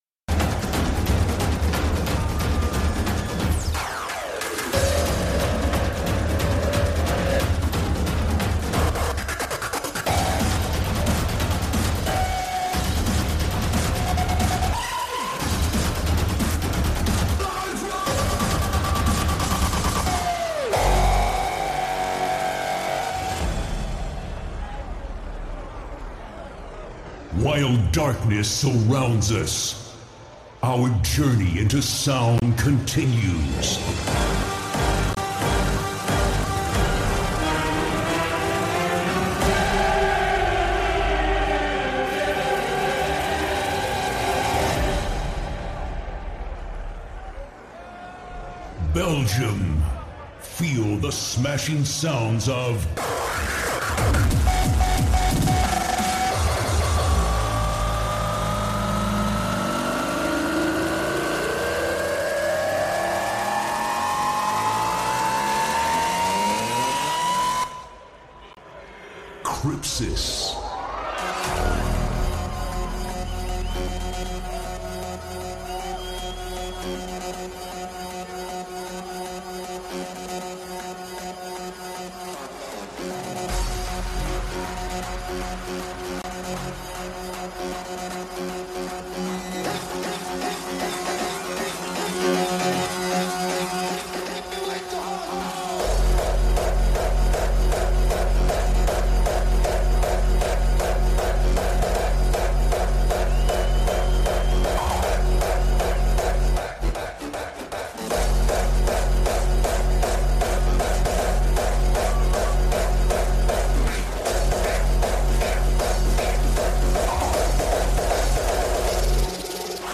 Also find other EDM Livesets, DJ Mixes
Liveset/DJ mix